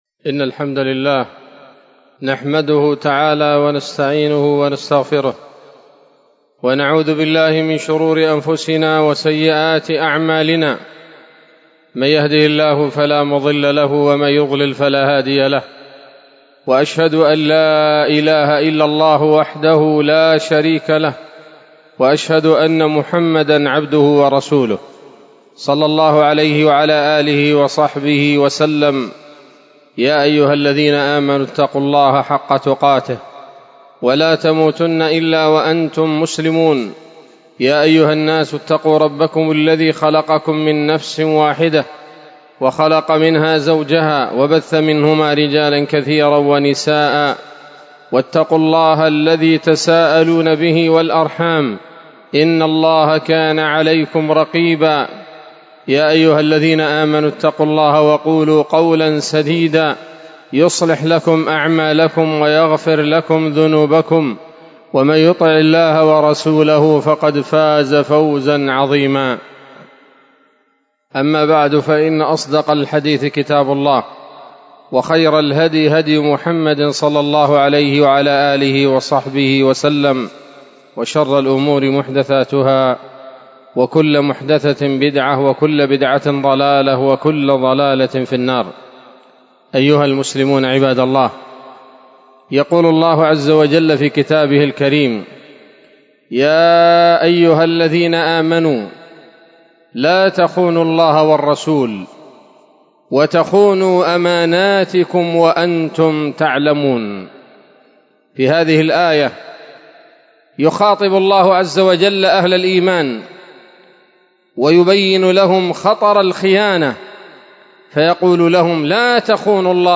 خطبة جمعة بعنوان: (( الخائنون لا يستحقون البقاء )) 1 ربيع الآخر 1446 هـ، دار الحديث السلفية بصلاح الدين